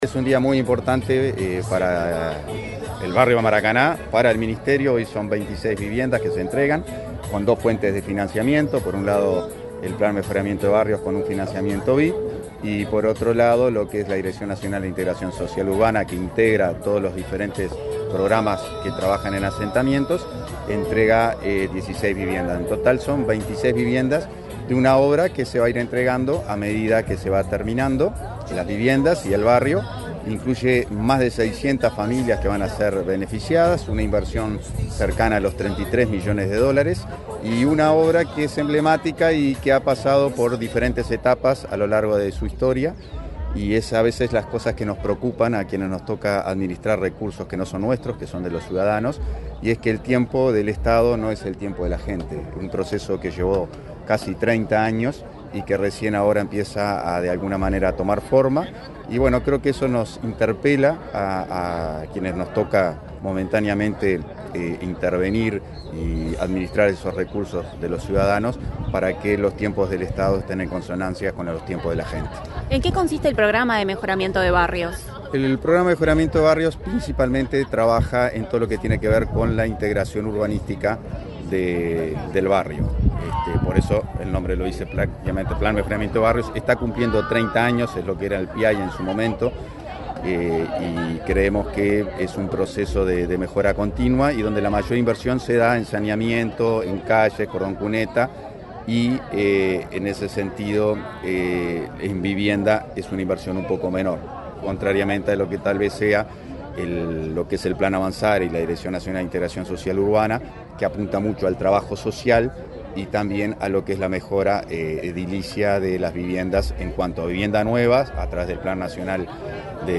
Declaraciones del ministro interino de Vivienda, Tabaré Hackenbruch
El ministro interino de Vivienda, Tabaré Hackenbruch, dialogó con la prensa, antes de participar en la inauguración de viviendas del plan Avanzar en